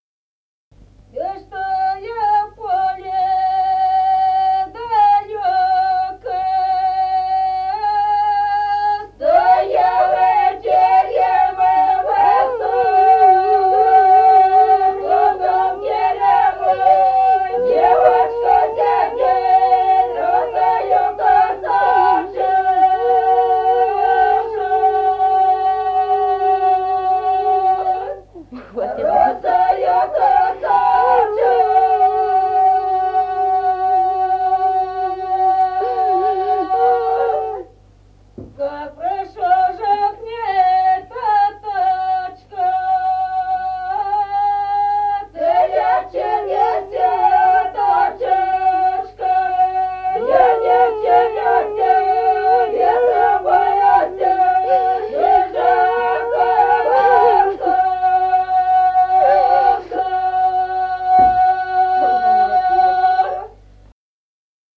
Файл:042а И чтой в поле далёко (с плачем) И0054-01.wav.mp3 — Фолк депозитарий